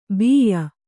♪ bīya